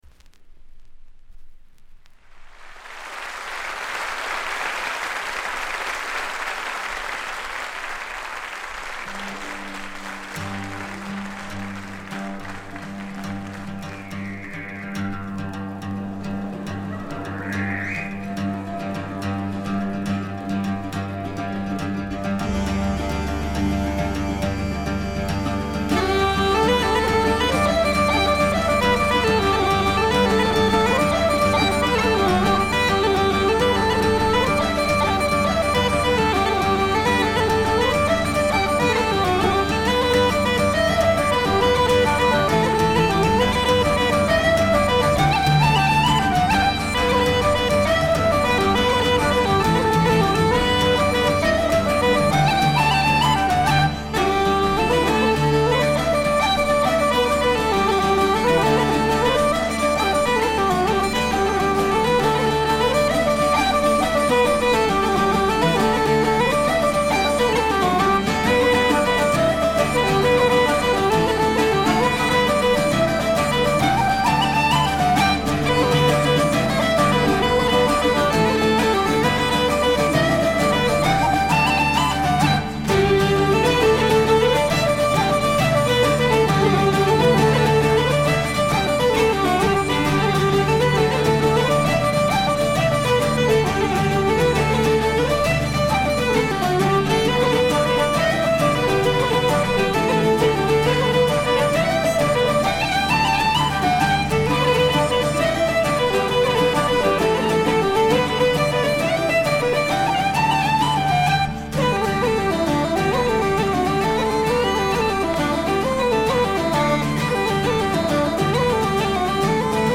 わずかなノイズ感のみ。
哀愁の器楽パート
試聴曲は現品からの取り込み音源です。
Fiddle
Flute